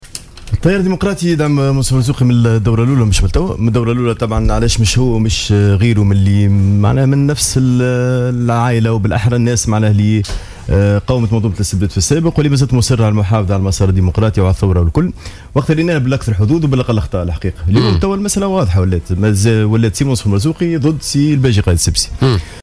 أكد محمد عبو ضيف برنامج "بوليتيكا" اليوم الثلاثاء أن التيار الديمقراطي سيواصل دعم المرشح المنصف المرزوقي في الدور الثاني من الانتخابات الرئاسية.